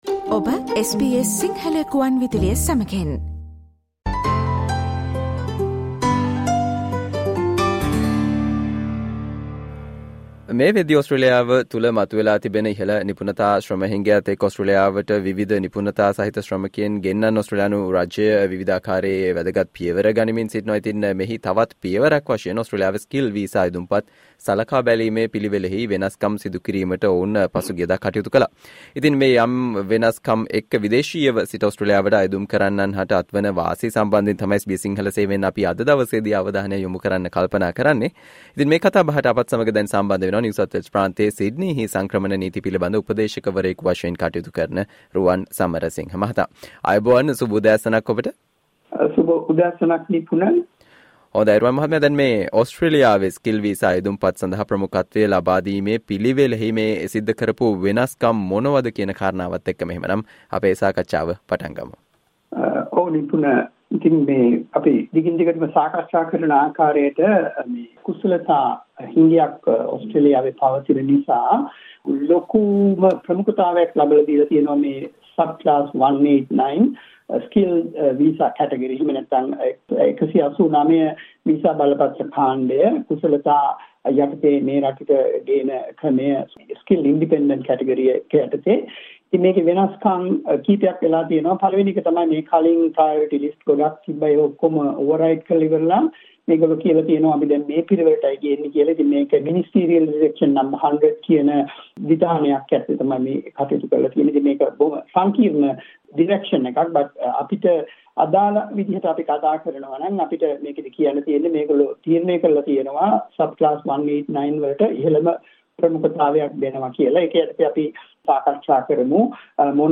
ඕස්ට්‍රේලියාවේ Skilled වීසා අයදුම්පත් සලකා බලන පිළිවෙලෙහි සිදු වූ වෙනස්කම් මෙන්ම ගුරුවරුන්ට සහ සෞඛ්‍ය සේවකයින් හට දින තුනෙන් වීසා ලබාදීමට, අලුතින් සිදු කල වෙනස්කම් සම්බන්ධයෙන් SBS සිංහල සේවය සිදුකල සාකච්චාවට සවන්දෙන්න